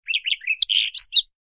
SZ_DG_bird_04.ogg